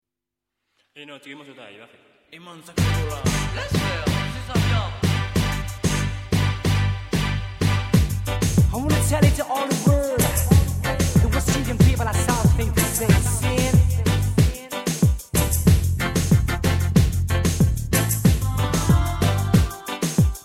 Le style : Zouk Love Antillais aux influences Reggae, Ragga